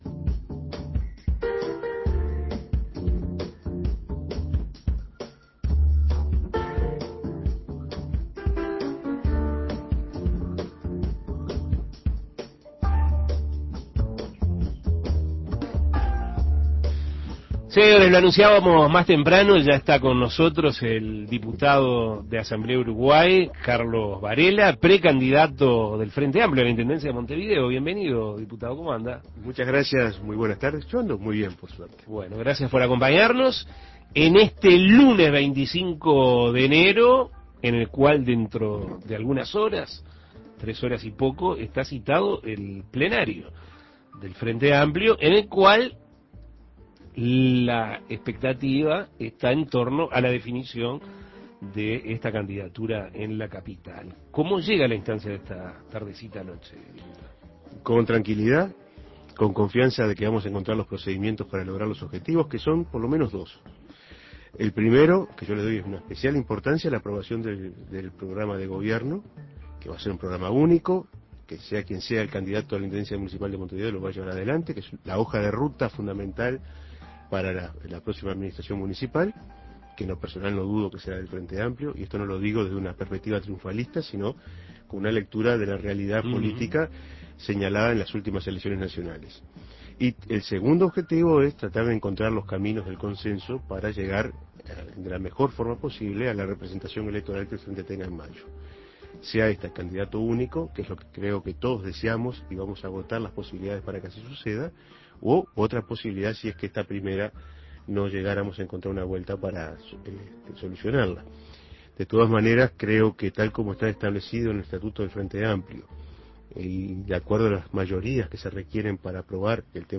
Carlos Varela, licenciado en Ciencias Políticas y candidato a intendente de Montevideo por Asamblea Uruguay (Frente Amplio) para el período 2010-2014 dialogó sobre su propuesta para, por medio de elección, convertirse en el jefe comunal capitalino, al tiempo que abordó los temas de la basura y el sistema de transporte. Escuche la entrevista.